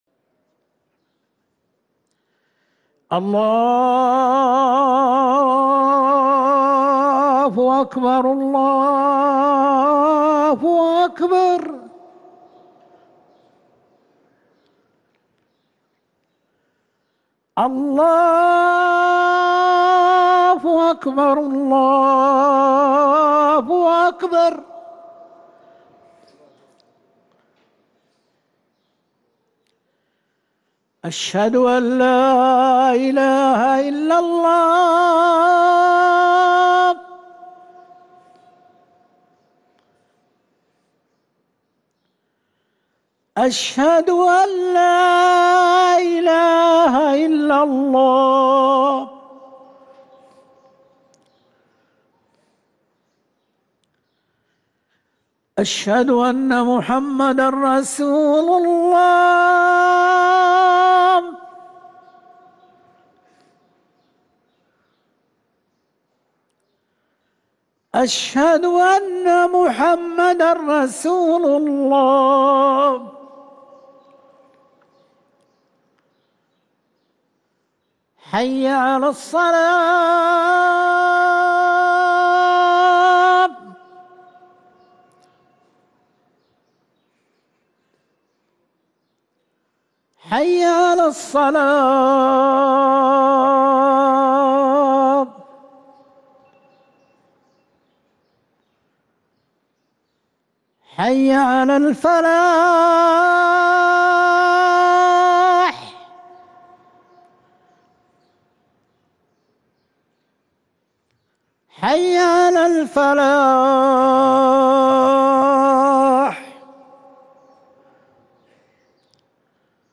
اذان الجمعة الاول